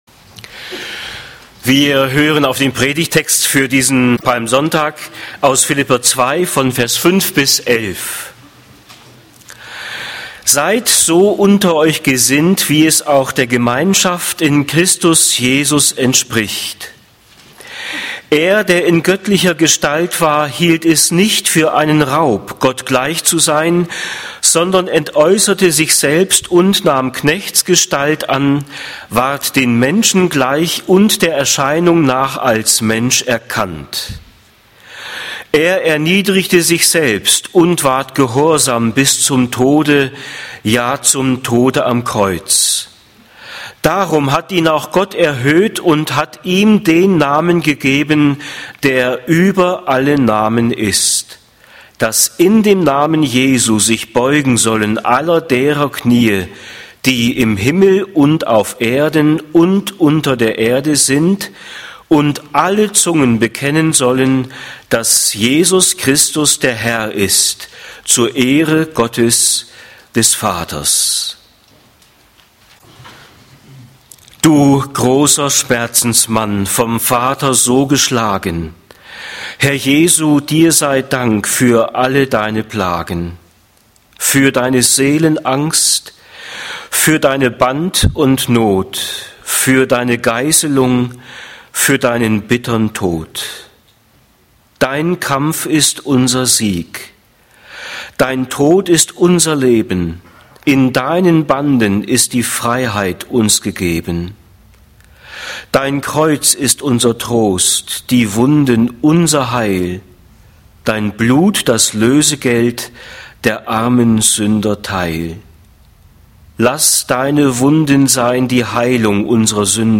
Jesus Christus - Sein unglaublicher Weg (Phil. 2, 5-11) - Gottesdienst